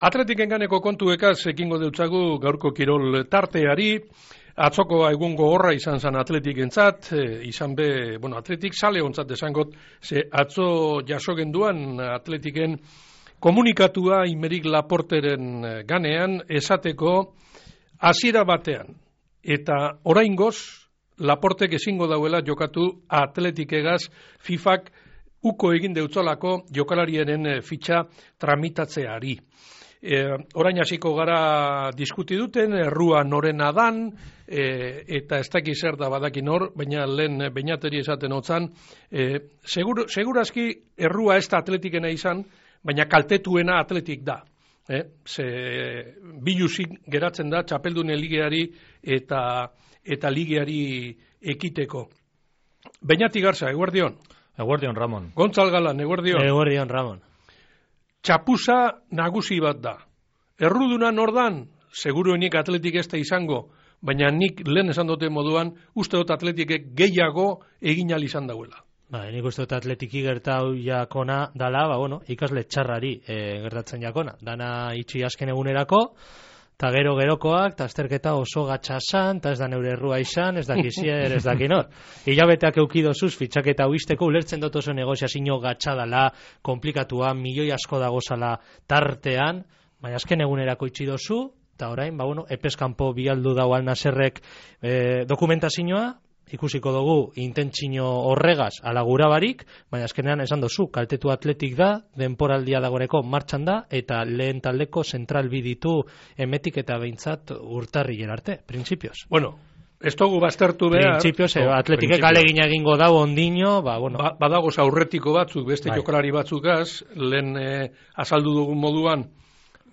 Athleticen ganeko tertulia | Bizkaia Irratia
ATHLETIC-TERTULIA.mp3